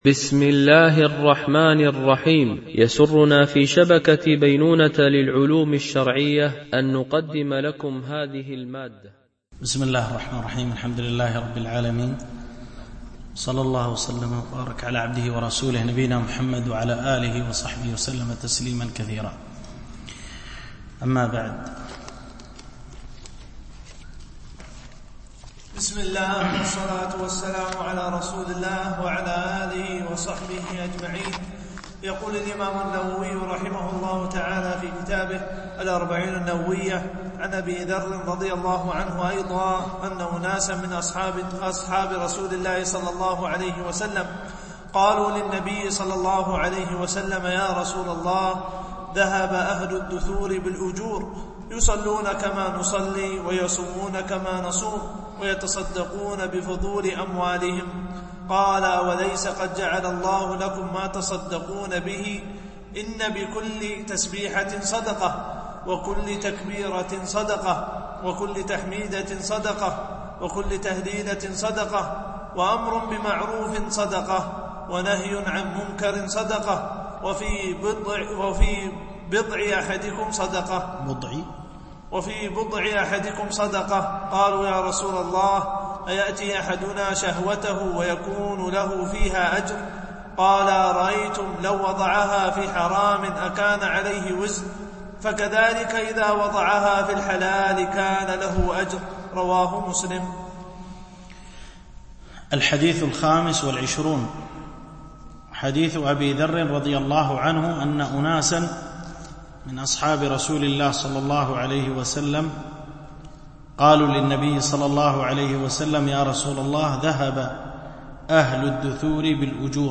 شرح الأربعين النووية - الدرس 13 (الحديث 25 - 26)